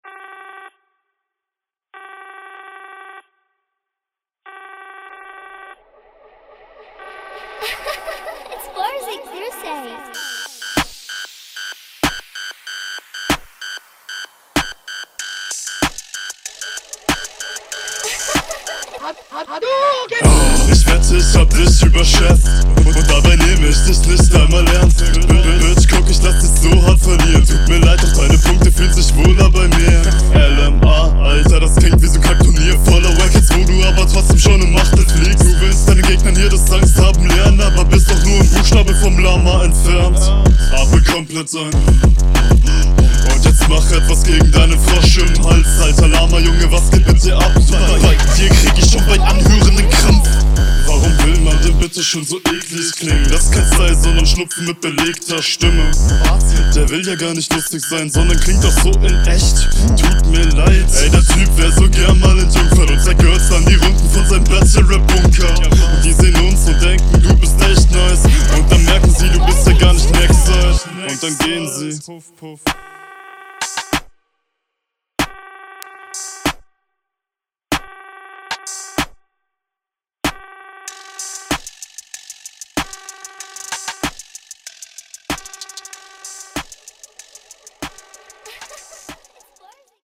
Flowst aber richtig gut drauf, das klingt richtig fett.
Stimme schön deep.
Stimmeneinsatz gefällt mir persönlich gut.